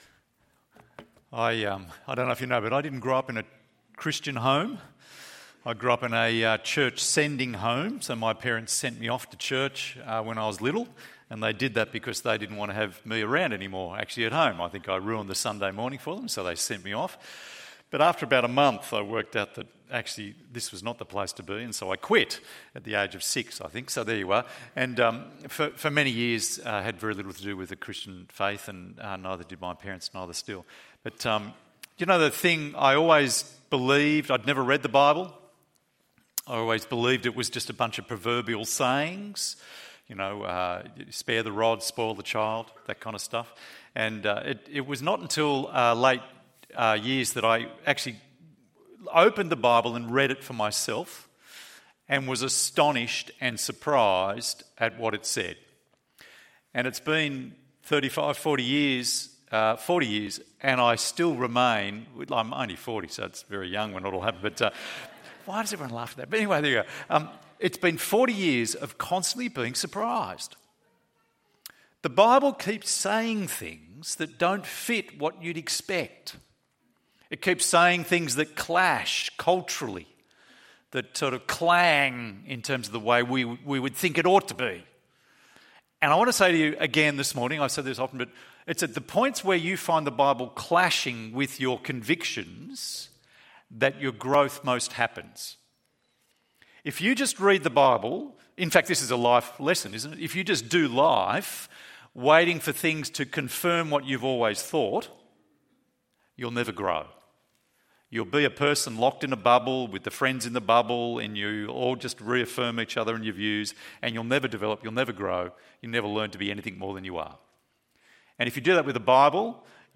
Protecting God's Household ~ EV Church Sermons Podcast